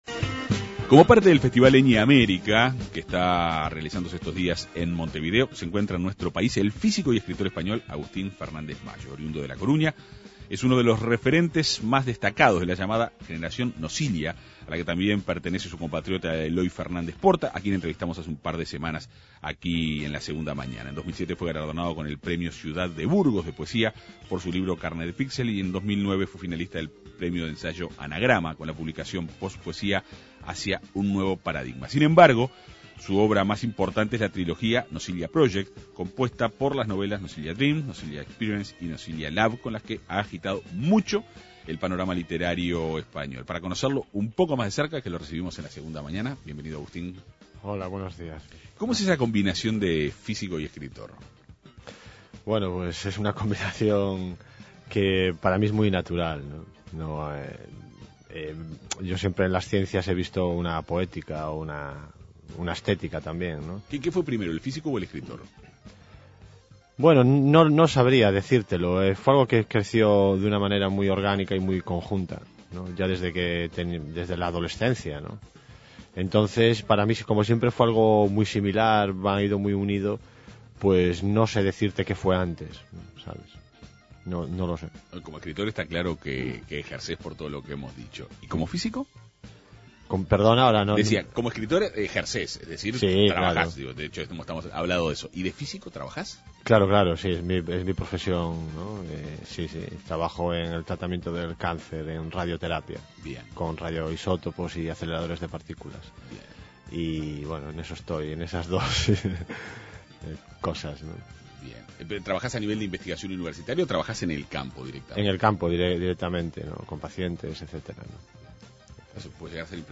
El autor dialogó en la Segunda Mañana de En Perspectiva.